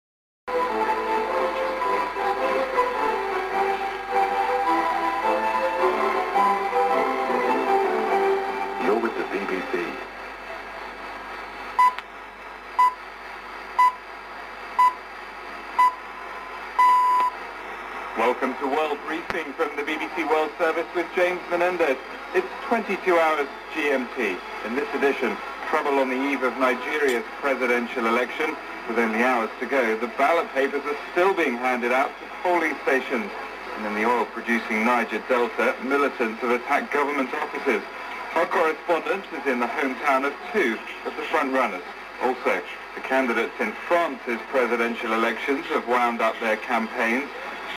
PROVA D’ASCOLTO
La sintonizzazione delle stazioni è’ laboriosa e richiede precisione e pazienza, date le scarse caratteristiche delle valvole e i semplici circuiti dell’epoca, la microfonicità delle valvole in modo particolare della rivelatrice, e dalle interferenze dovute alle stazioni adiacenti.
Comunque, come si è potuto sentire dal file mp3, il risultato complessivo è buono e mi ha ripagato di tutte le ore impiegate per restaurare, calcolare, provare ecc. ecc.